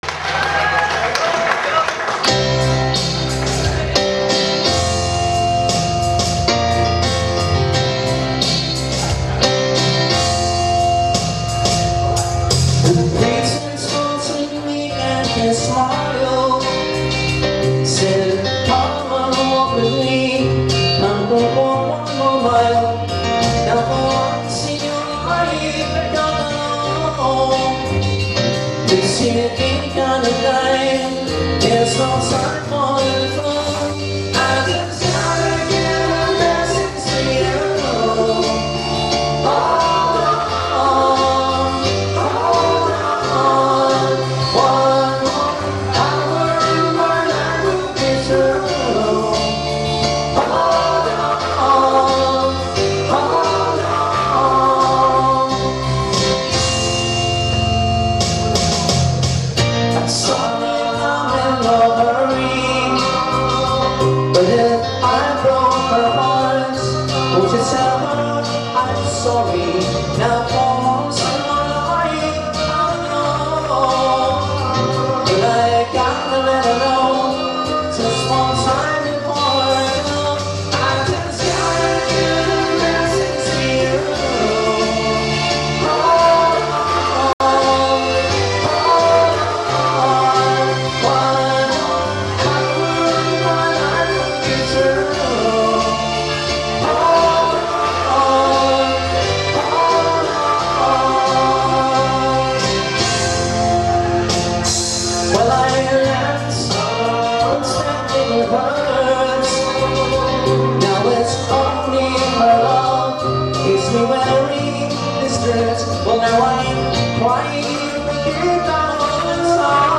His unique falsetto voice